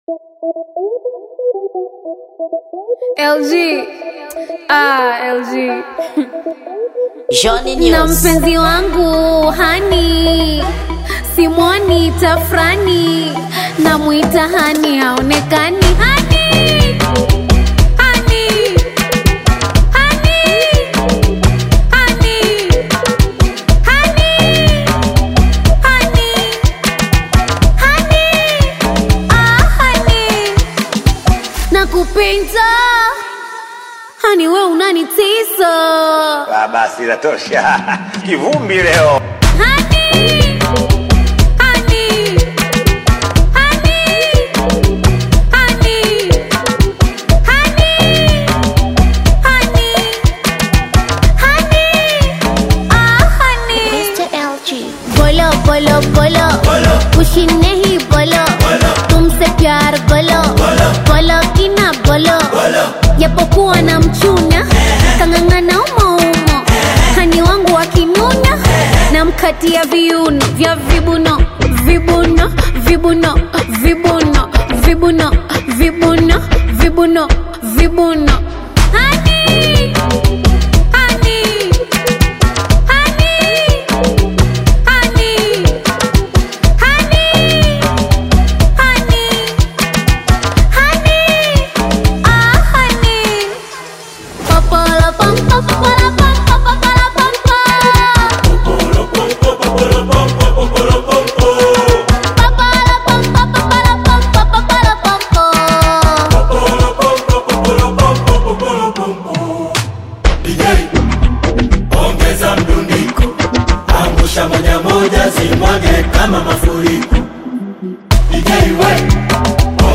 Gênero: Afro Beat